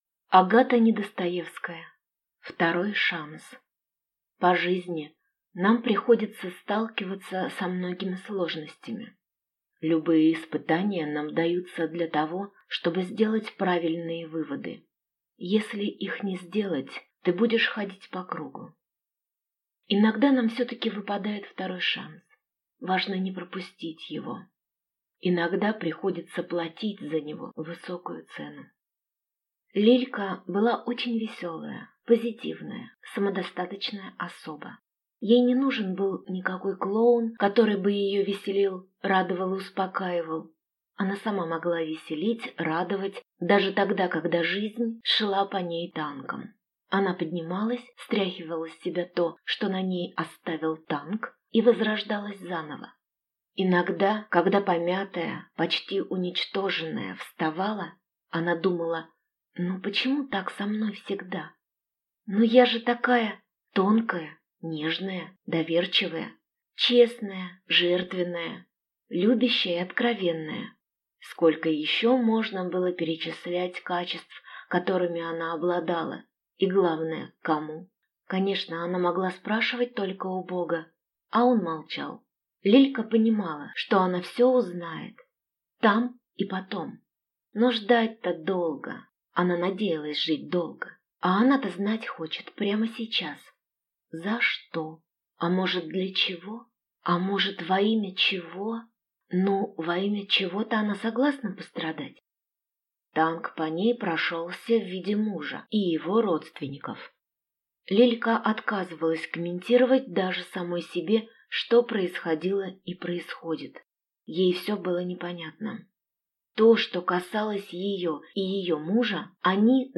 Aудиокнига Второй шанс